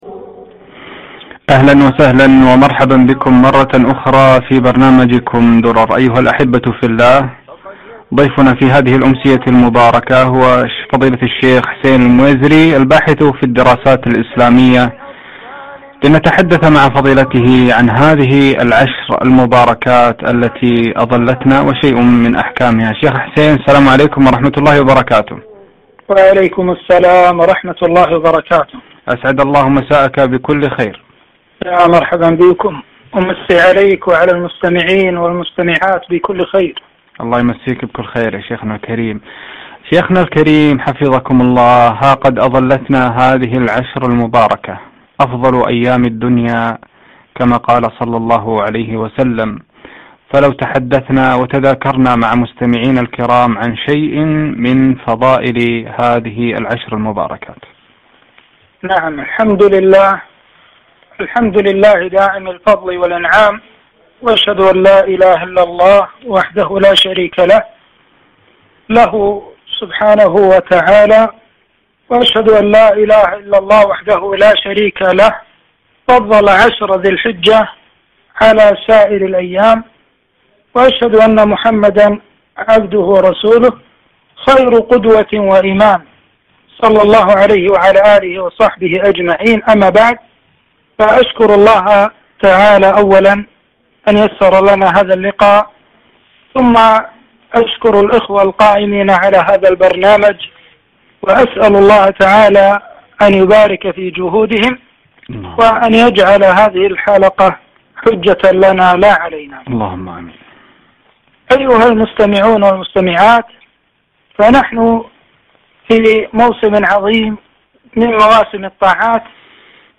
عشر ذي الحجة فضائلها وأحكامها لقاء عبر إذاعة القرآن - الكويت